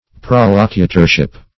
Prolocutorship \Prol`o*cu"tor*ship\, n. The office of a prolocutor.
prolocutorship.mp3